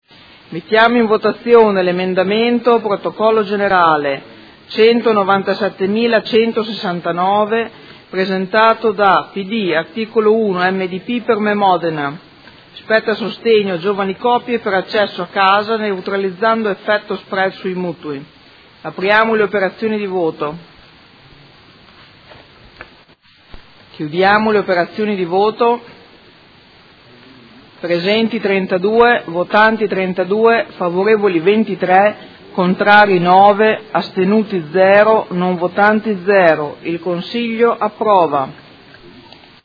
Seduta del 20/12/2018. Mette ai voti emendamento Prot. Gen. 197169